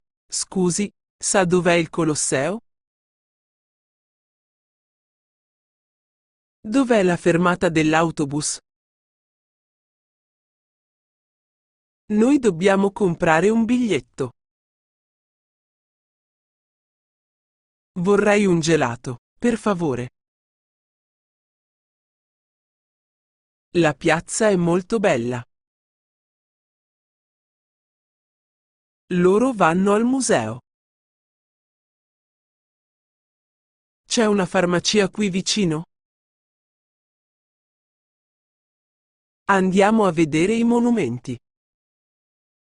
Dettato
dettato.mp3